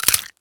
bone_break_neck_snap_crack_03.wav